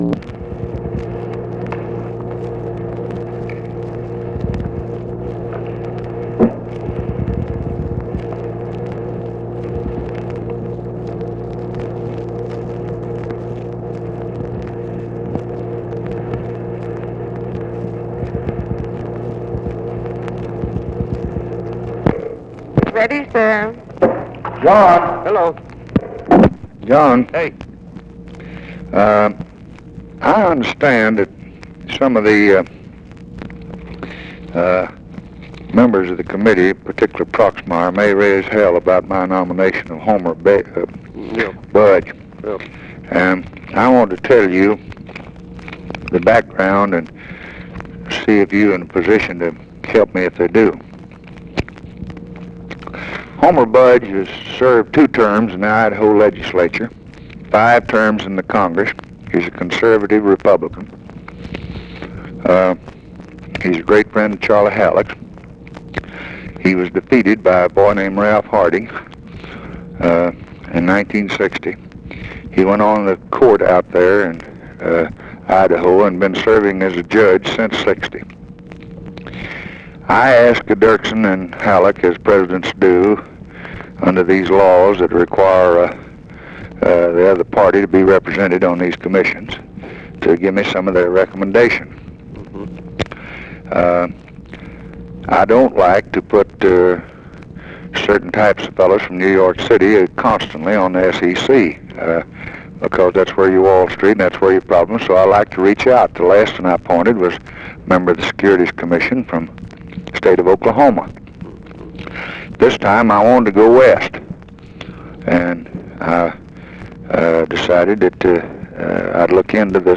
Conversation with JOHN SPARKMAN, June 22, 1964
Secret White House Tapes